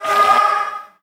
troop_death2.ogg